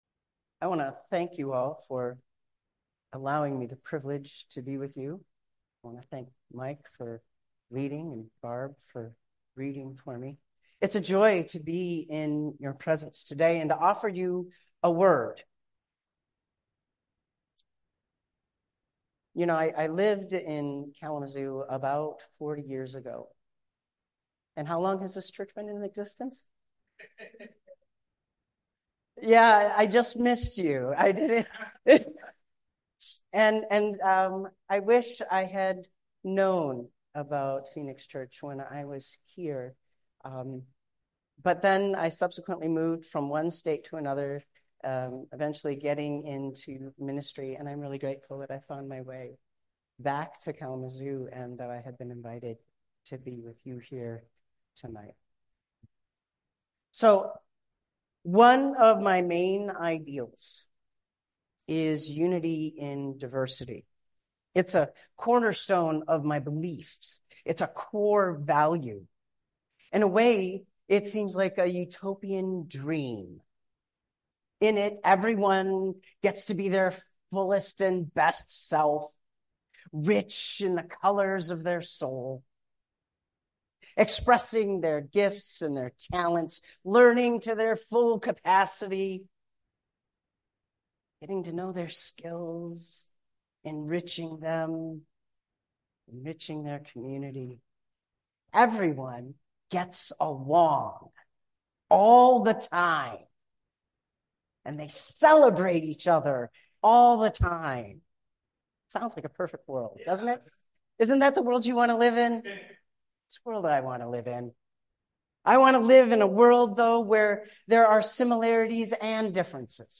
Message from guest speaker